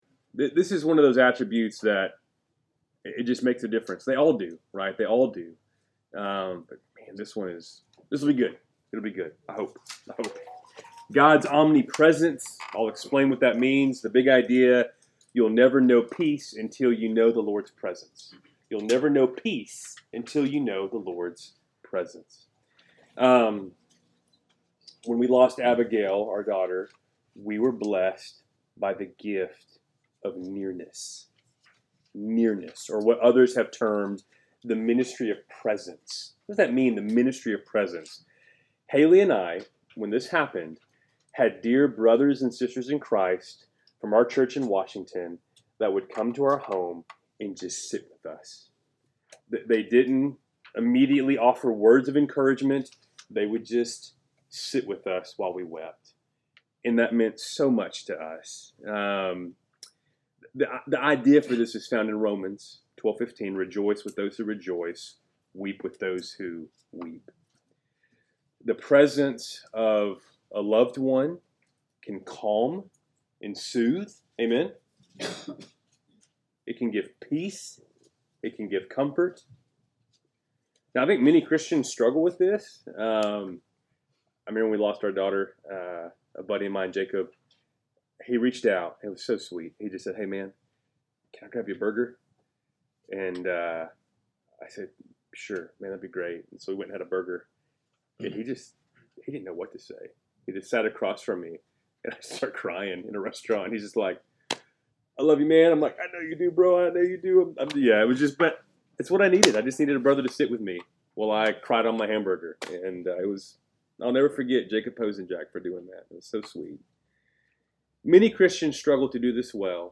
Keltys Worship Service, October 6, 2024